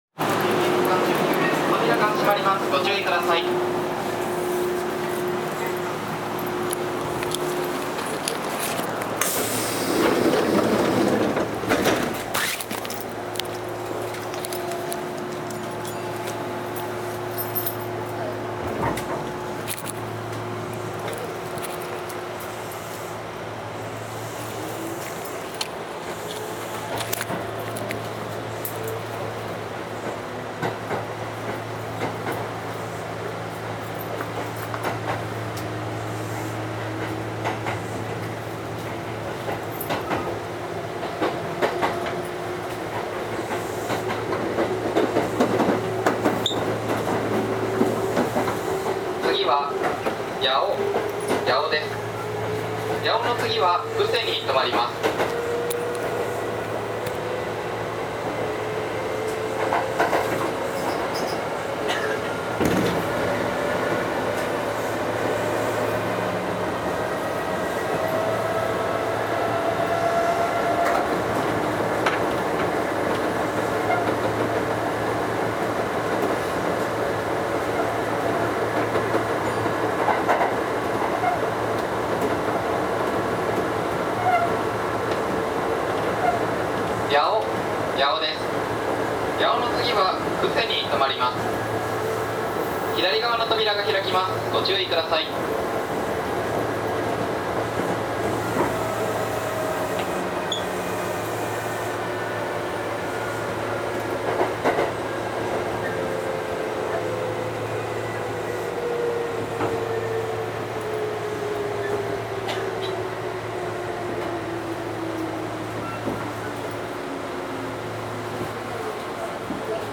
走行音
録音区間：河内山本～近鉄八尾(区間準急)(お持ち帰り)